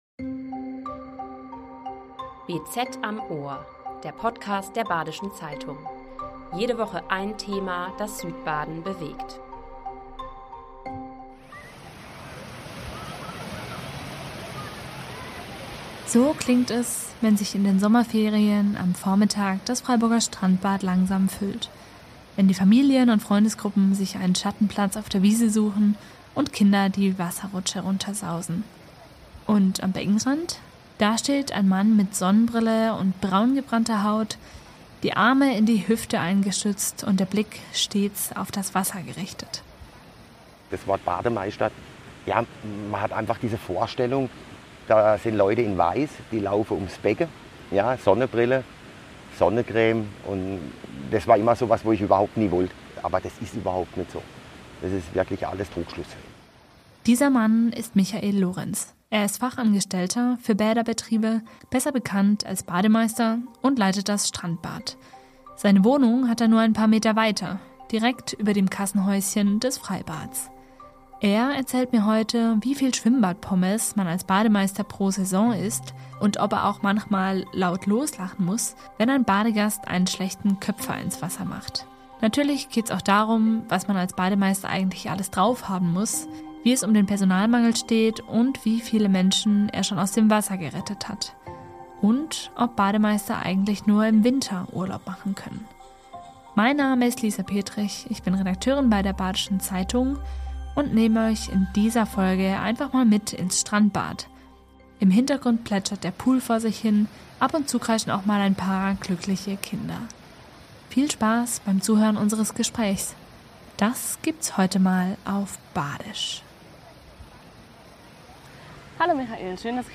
Podcast im Freiburger Strandbad: Alles, was man über einen Bademeister wissen muss ~ BZ am Ohr Podcast